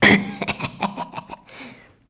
LAUGHTER.wav